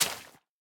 Minecraft Version Minecraft Version 1.21.5 Latest Release | Latest Snapshot 1.21.5 / assets / minecraft / sounds / block / sponge / wet_sponge / break2.ogg Compare With Compare With Latest Release | Latest Snapshot